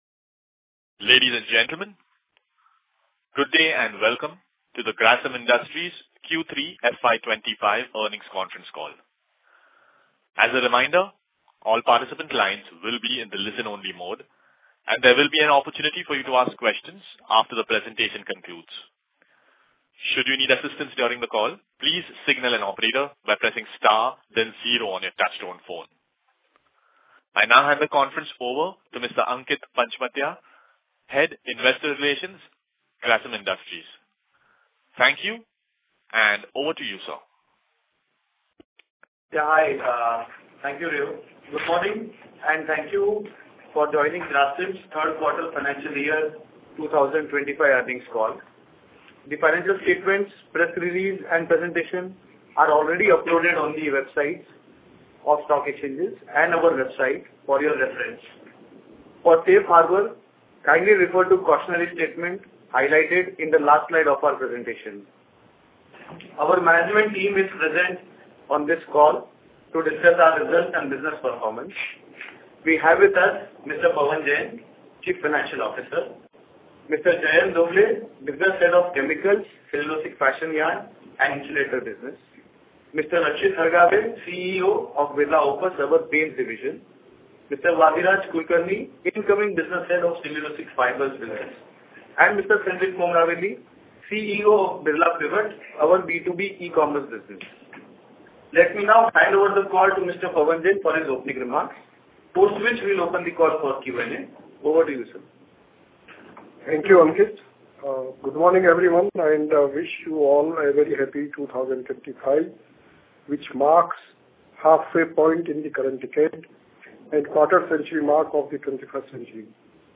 Concalls
grasim-conference-call-q3fy25.mp3